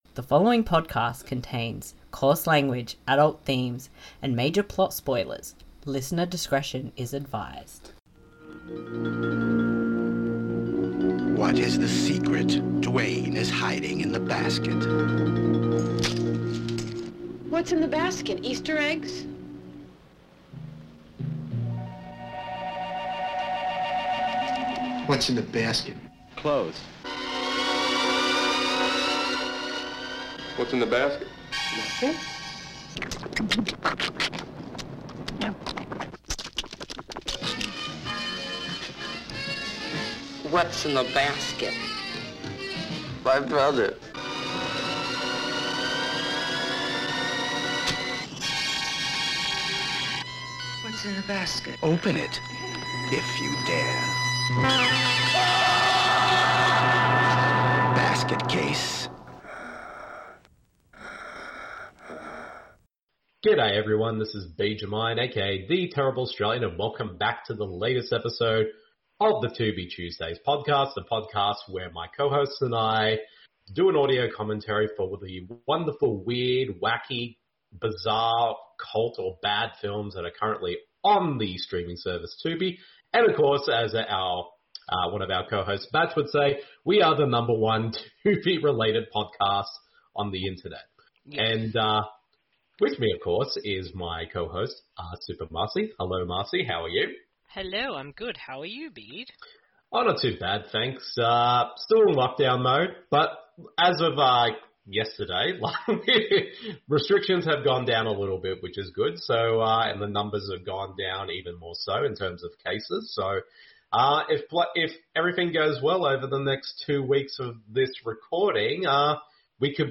Because we have watched the films on Tubi, as it is a free service there are ads, however we will give a warning when it comes up, so you can still listen along.